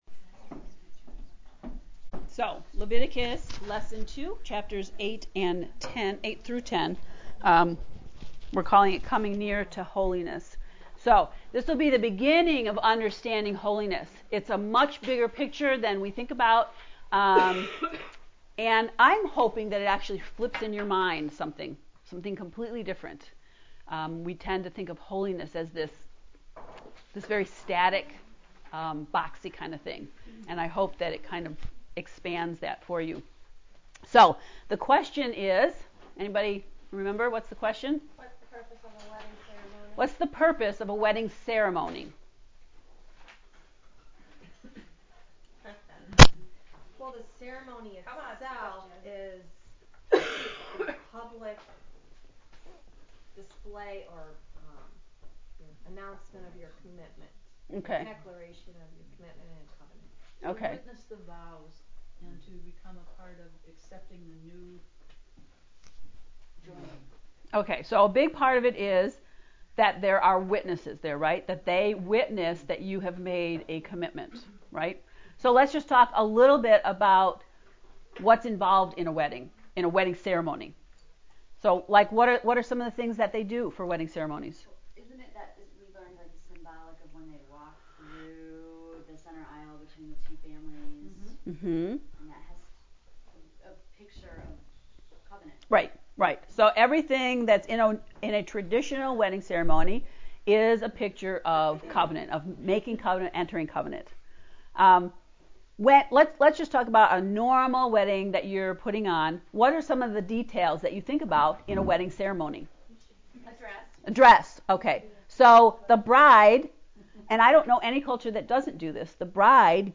LEVITICUS lesson 2
levlecture2.mp3